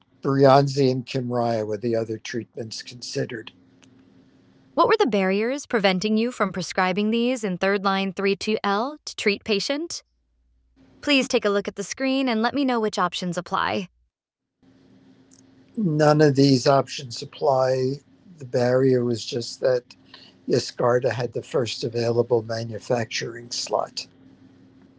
For this pilot study, we recruited 15 hematologist-oncologists to provide detailed patient charts through our conversational patient scribe.